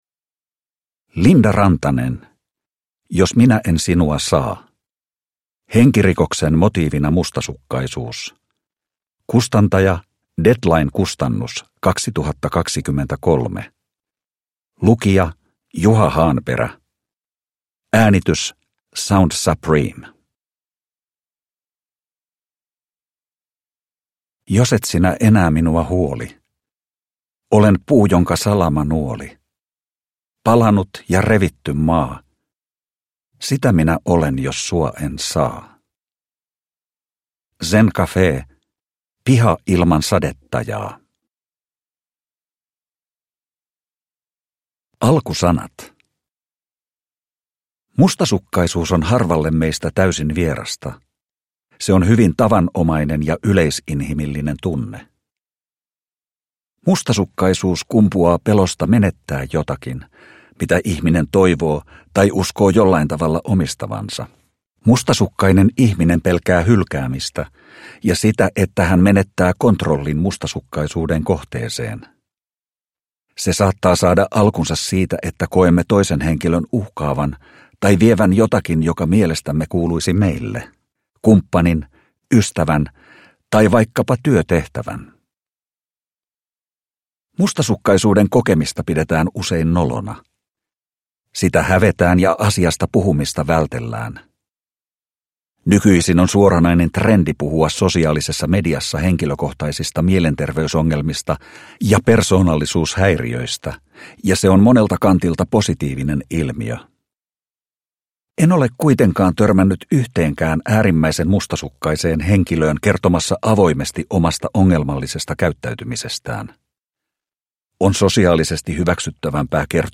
Jos minä en sinua saa (ljudbok) av Linda Rantanen